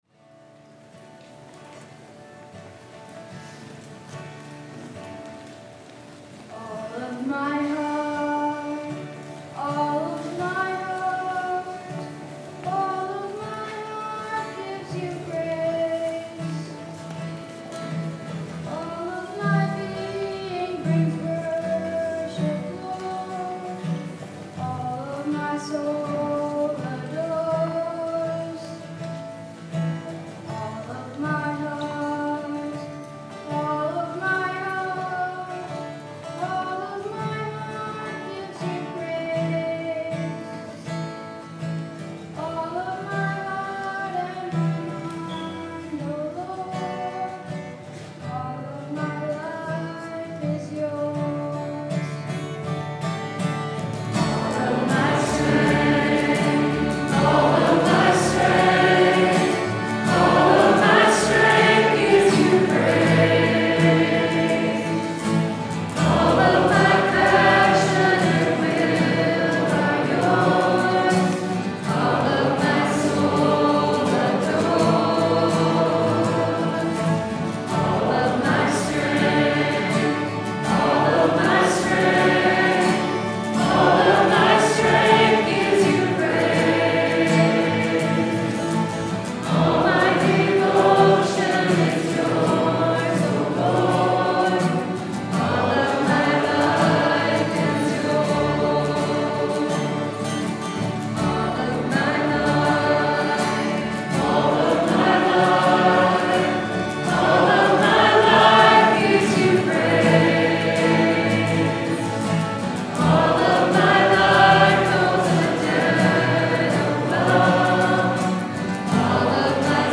Sunday was a nice, low key Sunday that saw God’s people at COS doing what God’s people do on a Sunday morning: hearing the Word, participating in the Sacraments, and singing together.
All of My Heart is my new favorite song. It’s got everything you could want from a worship chorus: it’s simple, memorable and heartfelt. It’s a wonderful song of dedication.